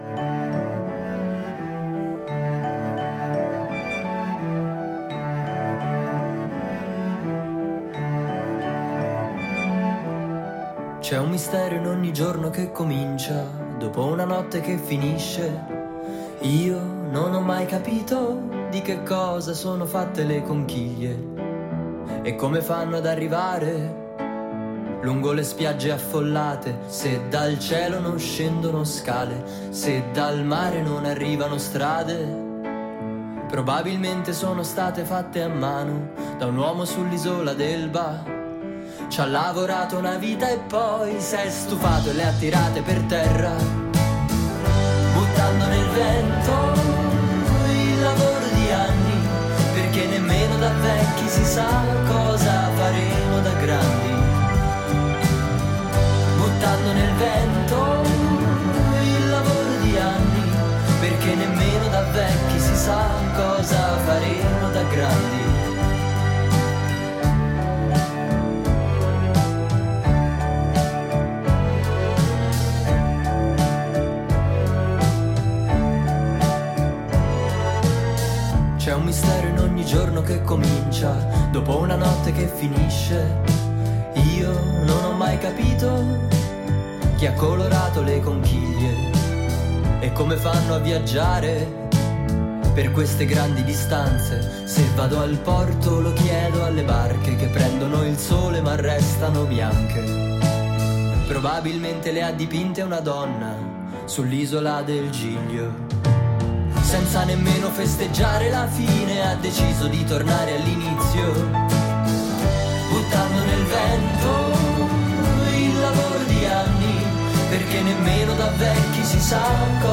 All'interno di Groovy Times, intervista telefonica al cantautore Lucio Corsi, che racconta il suo terzo album "Cosa faremo da grandi?", e qualche anticipazione sul concerto all'Auditorium Parco della musica di Roma del 1 marzo 2020.